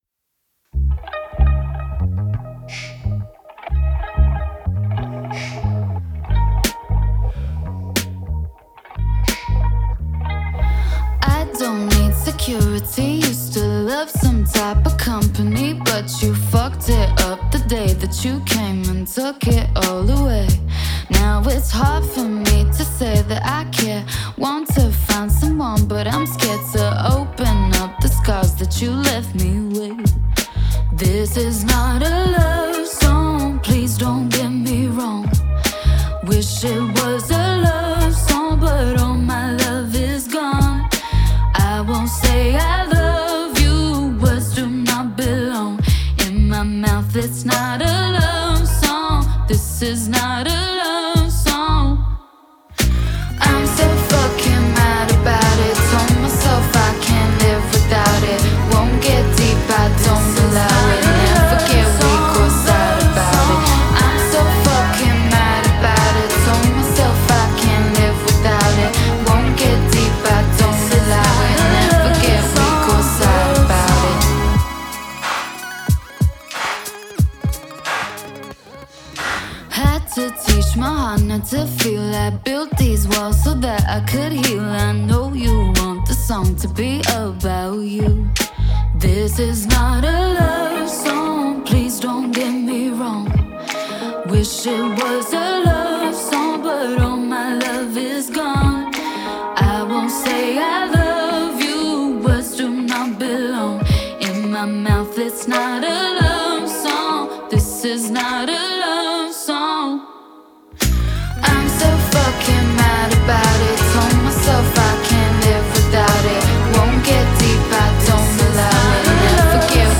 мощный вокал и эмоциональную интерпретацию